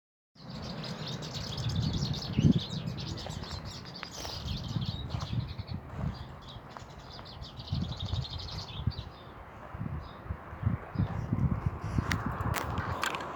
Black-chinned Siskin (Spinus barbatus)
Sex: Male
Life Stage: Adult
Detailed location: Lago Nahual Huapi
Condition: Wild
Certainty: Photographed, Recorded vocal